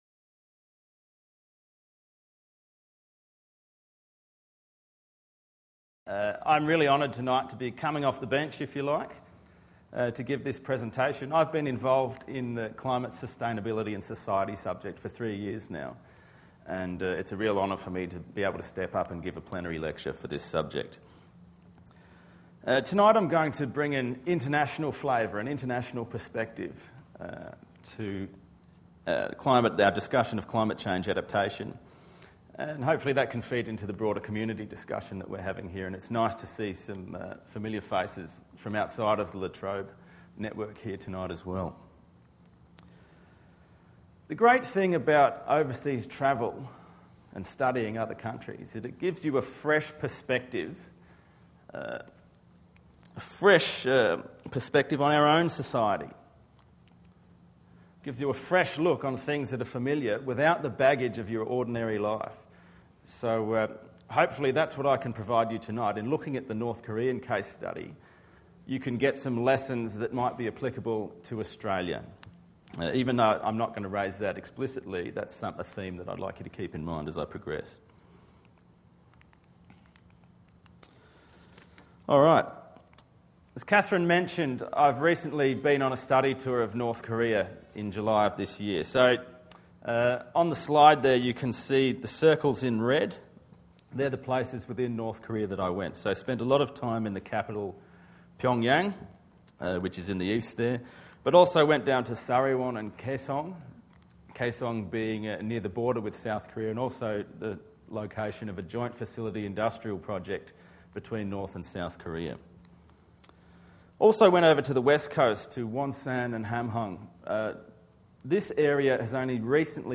It was my great privilege to deliver the second Climate and Society plenary lecture for 2012 on Climate Change Adaptation in North Korea, drawing on my academic research and observation from my recent field trip to North Korea in July.